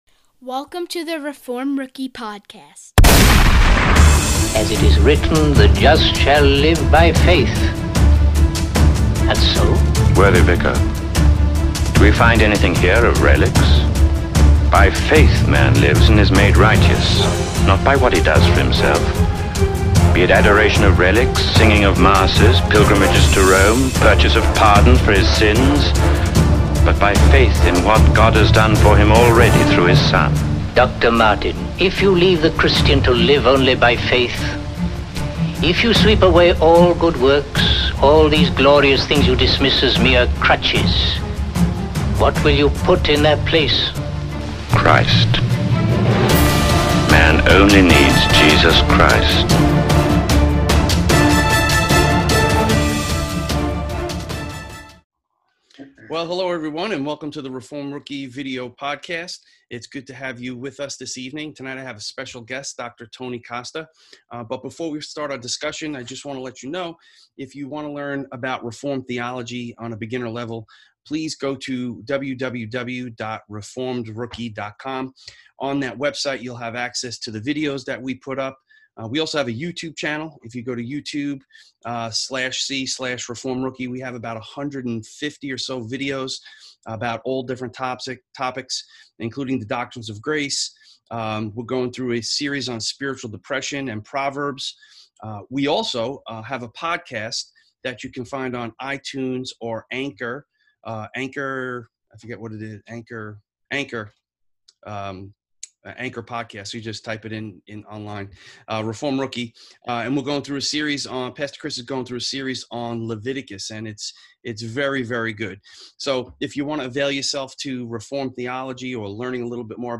Baptism Discussion